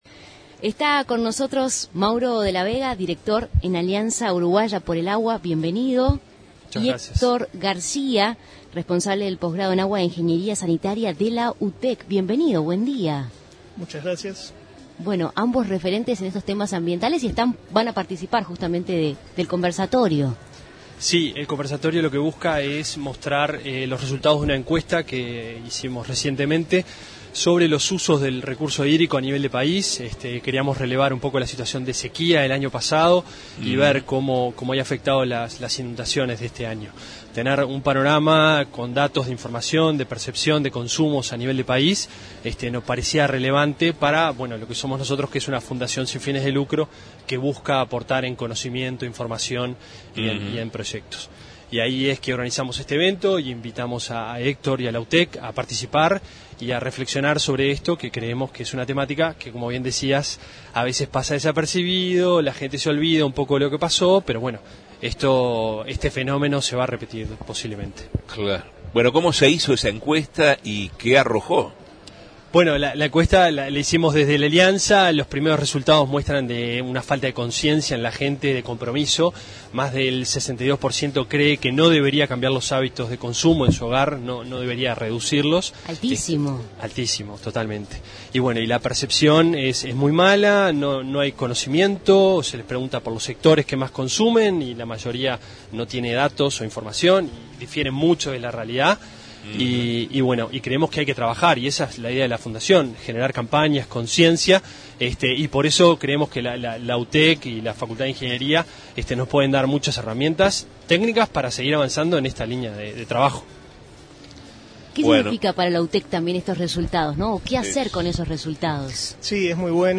Entrevista
Desde la Expo Uruguay Sostenible, en el Antel Arena